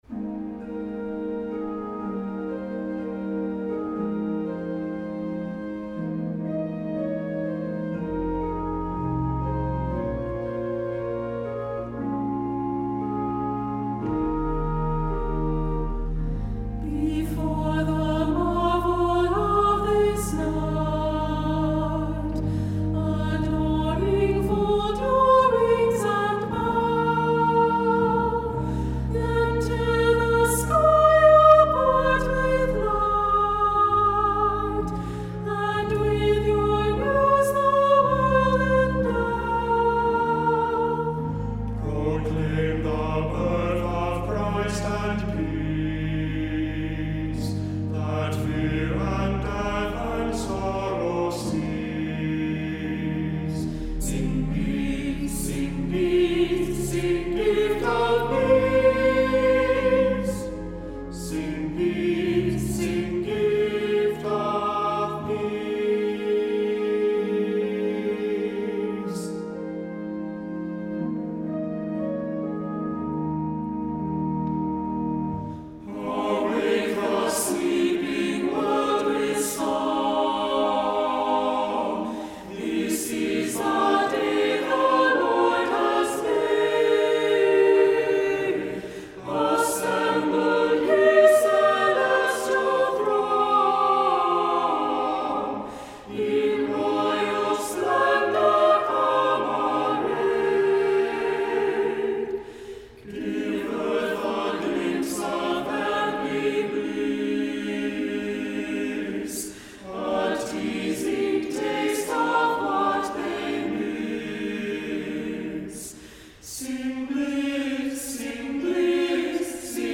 Voicing: "SATB divisi"